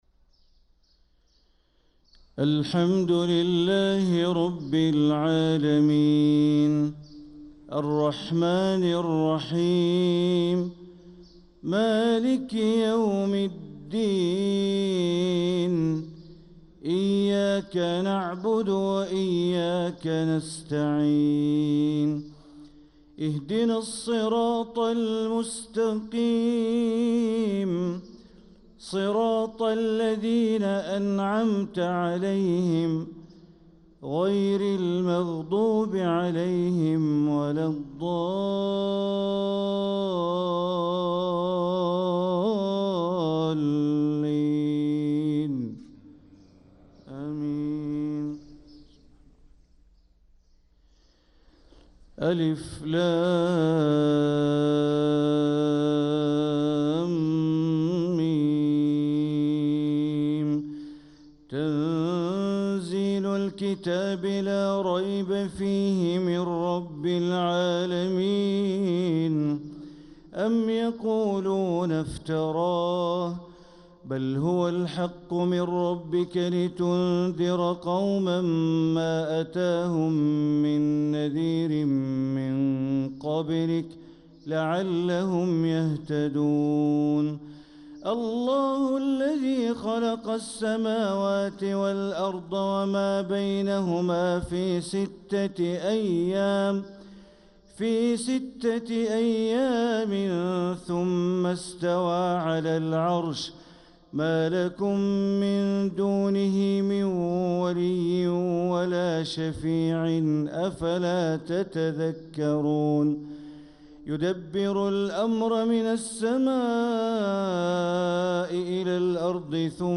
صلاة الفجر للقارئ بندر بليلة 17 رجب 1446 هـ
تِلَاوَات الْحَرَمَيْن .